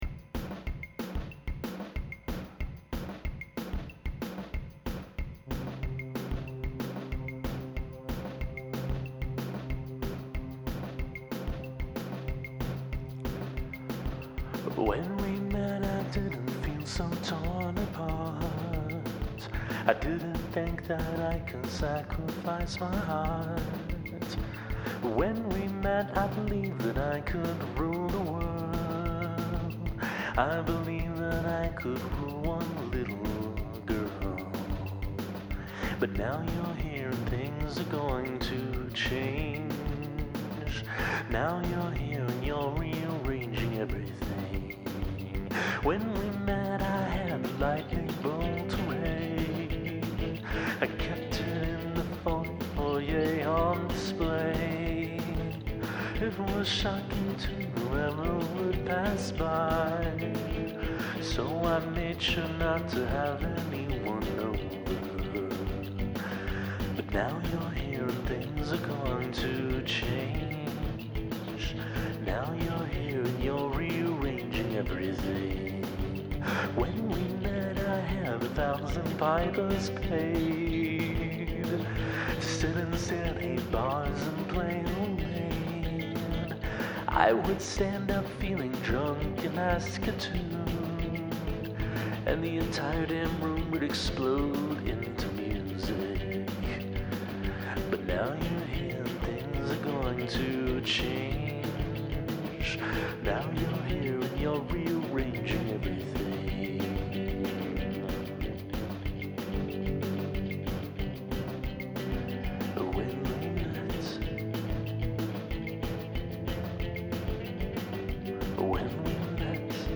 I FURTHER ENCOURAGE THIS SYNTH/DRUM MACHINE PATH I'm going to have to bring my A game when I resurface here (soon!)
Funny, good storytelling, awesome new-wave energetic.
Since the vocals have a lot of mid-high and the instrument parts are focused on bass, I'll bet you could get away with mixing the instruments a little louder if you wanted to.